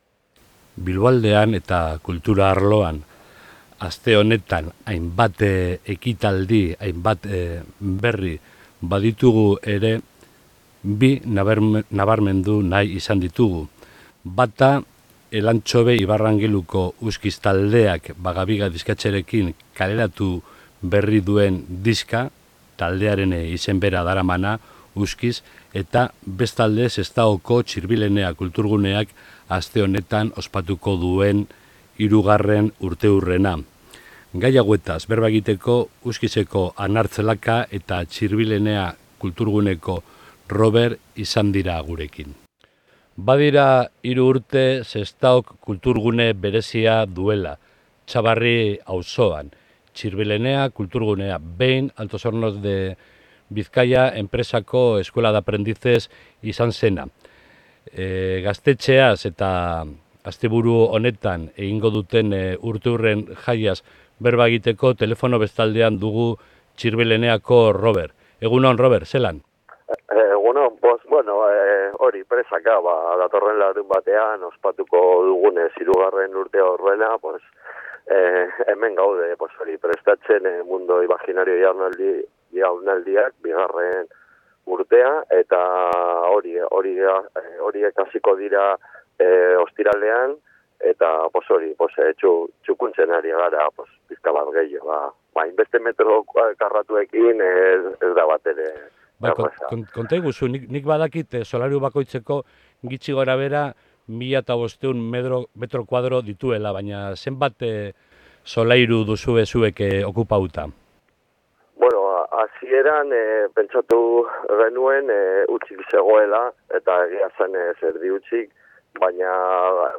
Bizkaiko Labe Garaiak zeuden eremu batean zegoen Aprendiz eskola: duela 3 urte gazte batzuek okupatu zuten eta horretan jarraitzen dute, lokalari hainbat erabilera emanez. Elkarrizketa